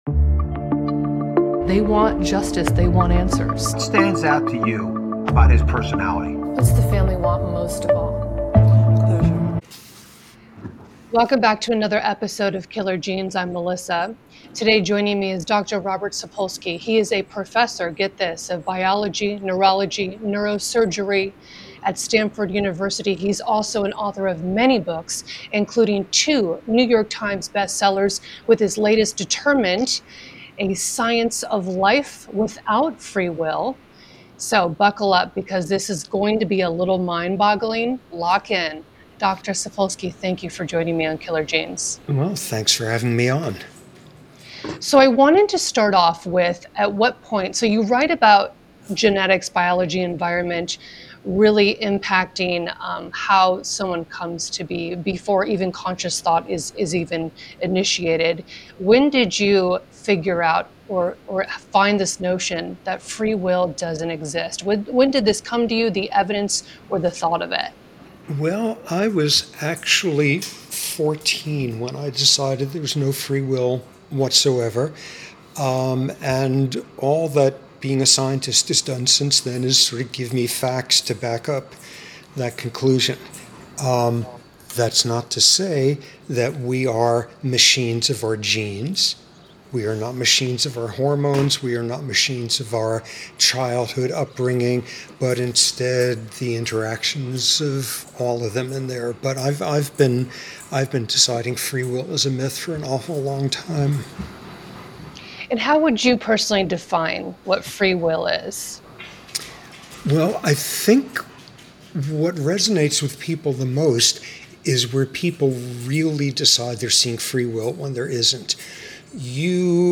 Neuroscientist Dr. Robert Sapolsky explains why we have no free will, why testosterone doesn’t cause aggression, how our biology and upbringing script our behaviors long before we act—and why the concept of free will may be nothing more than a comforting illusion.
This conversation challenges the foundation of justice itself—and asks whether understanding could one day replace blame.